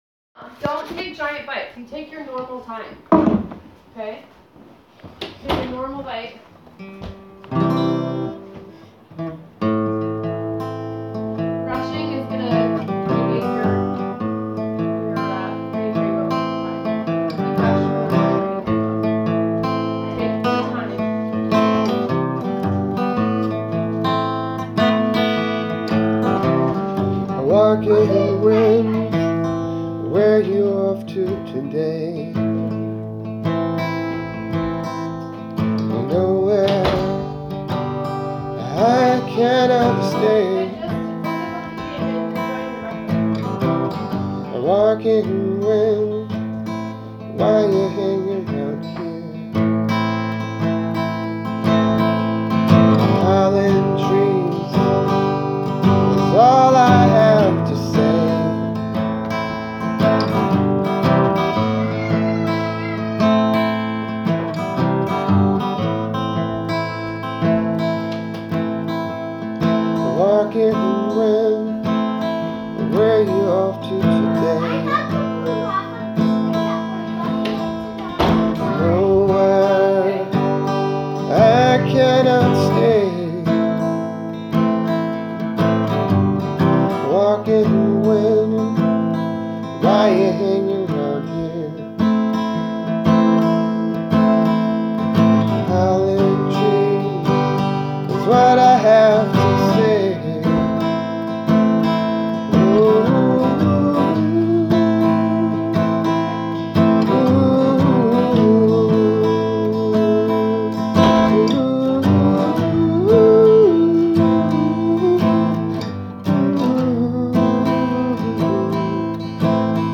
file) 1.89 MB Am , C , G 1